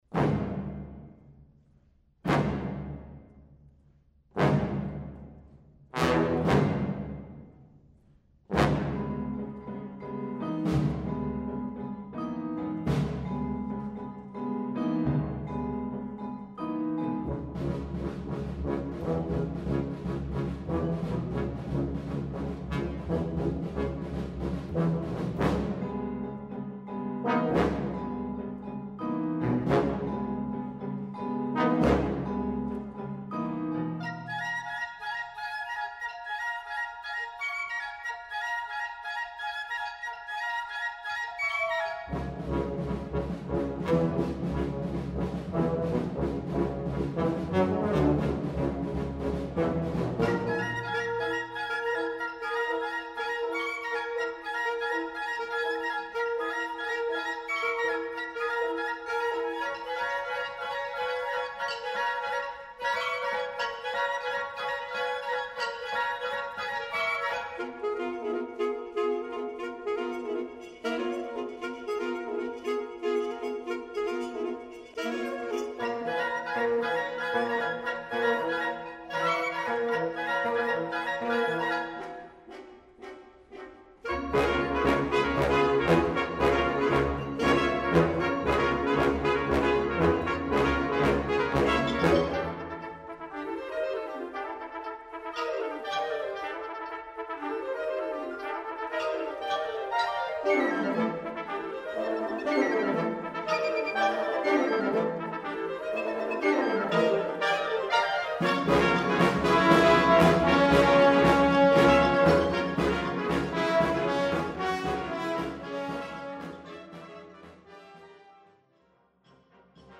Besetzung: Blasorchester
wonderfully energetic work